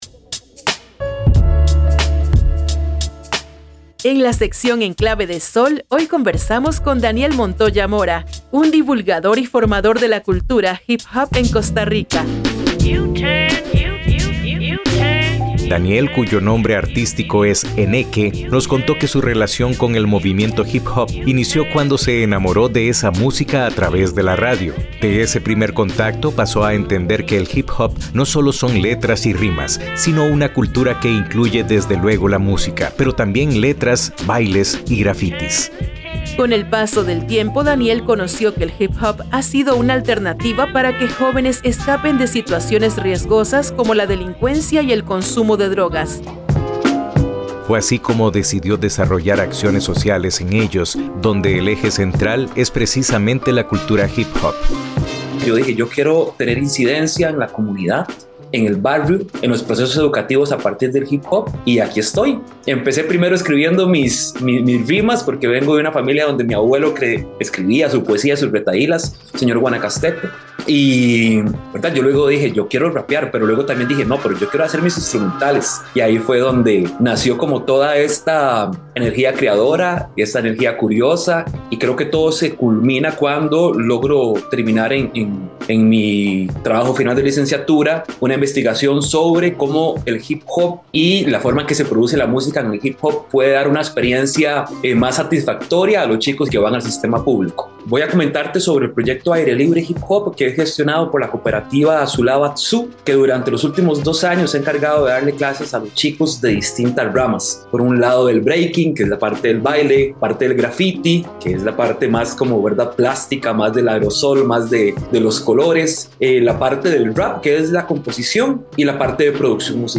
Cápsulas